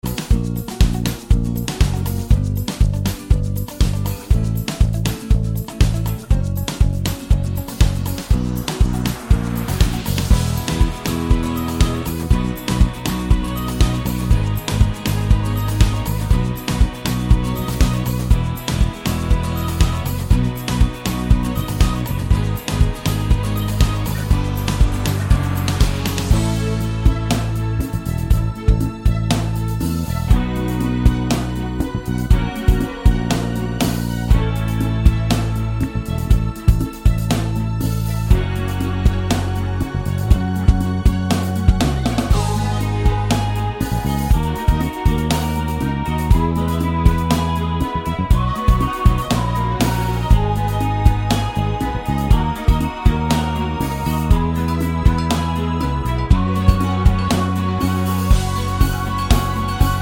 For Solo Singer Soundtracks 2:58 Buy £1.50